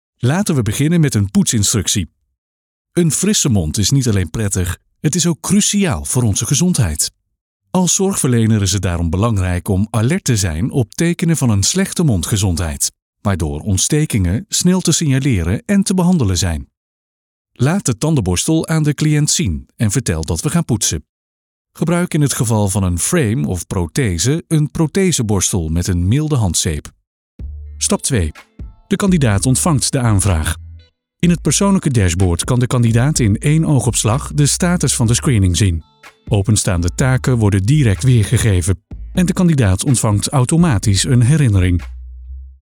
Reliable, Friendly, Corporate
E-learning